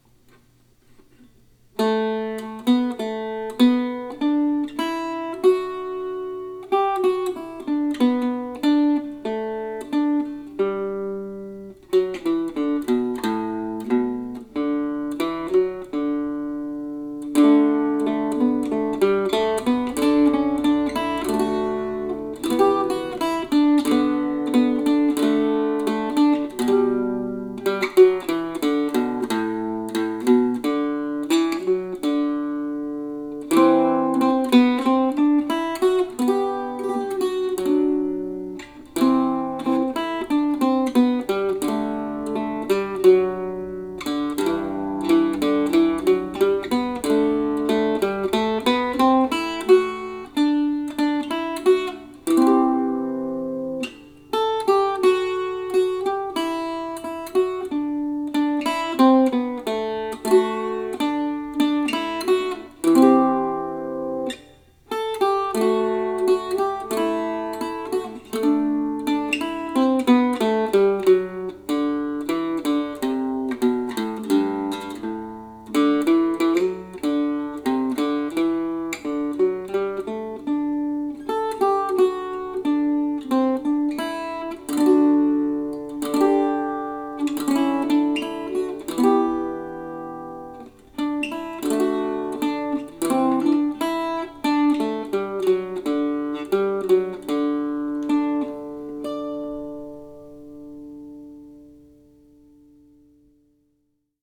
for mandocello or octave mandolin.